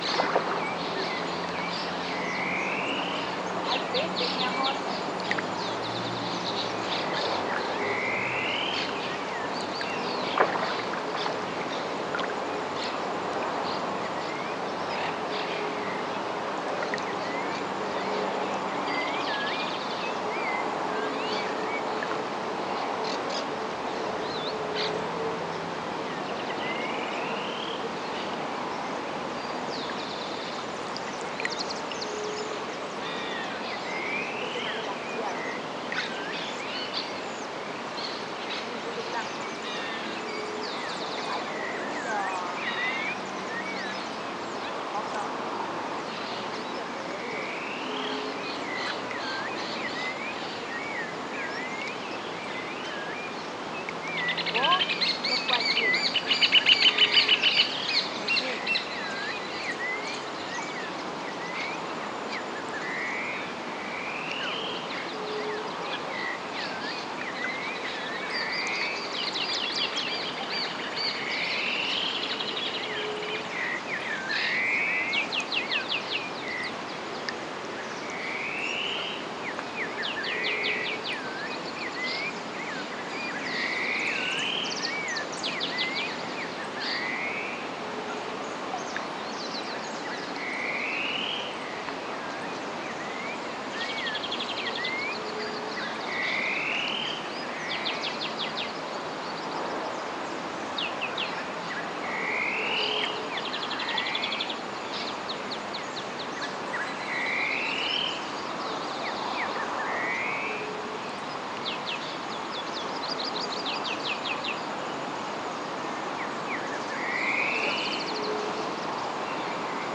Balneario municipal 13.15 hs. 18 de Diciembre 2020
esf-san-jose-del-rincon-arroyo-ubajay-balneario-municipal.mp3